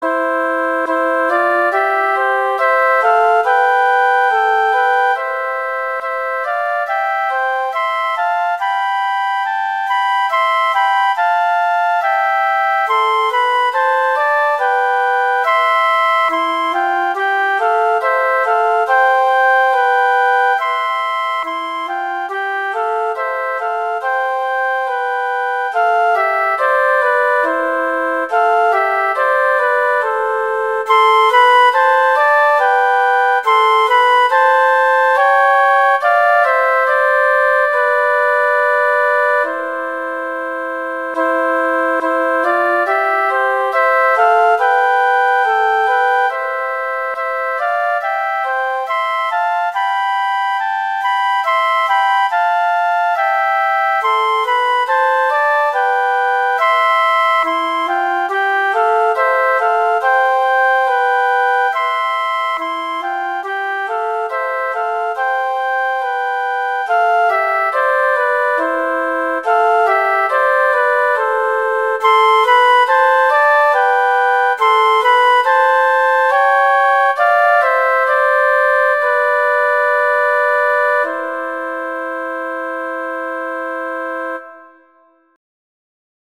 Instrumentation: two flutes